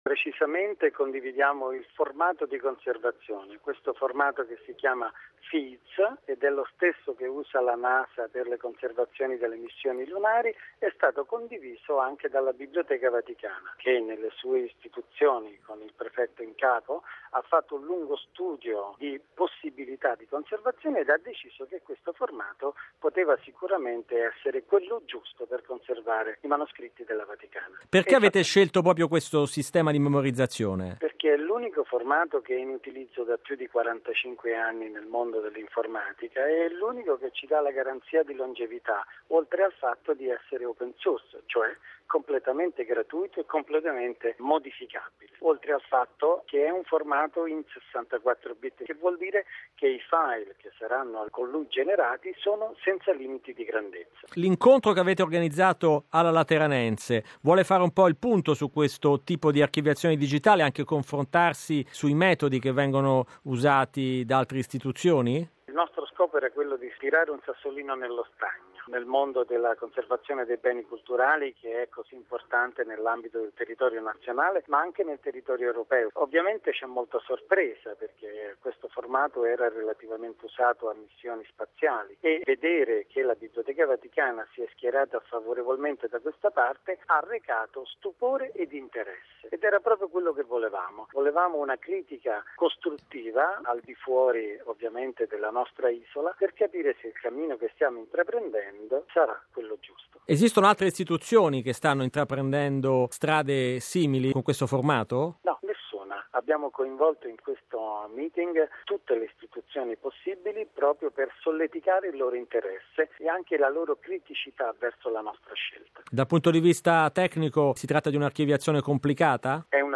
ne ha parlato con